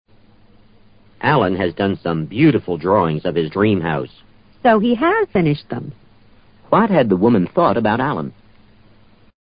托福听力小对话【60】